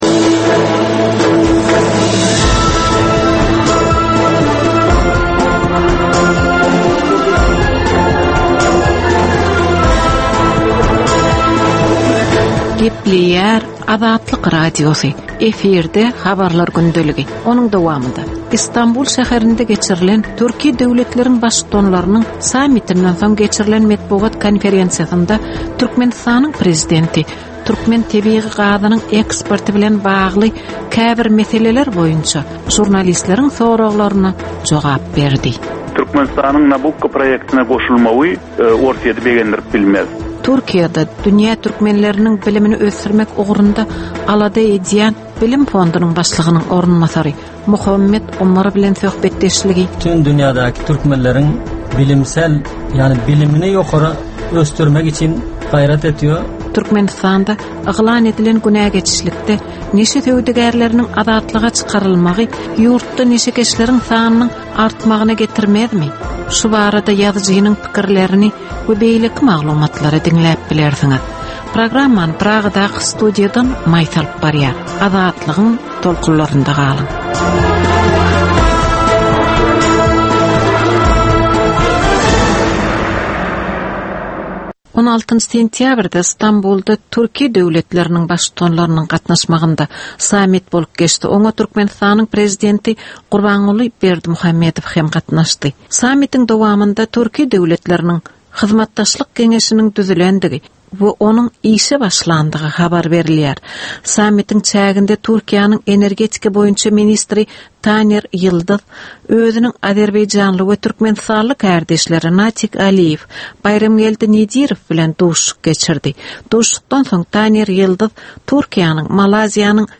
Türkmenistandaky we halkara arenasyndaky soňky möhüm wakalar we meseleler barada ýörite informasion-analitiki programma. Bu programmada soňky möhüm wakalar we meseleler barada giňişleýin maglumatlar, analizler, synlar, söhbetdeşlikler, kommentariýalar we diskussiýalar berilýär.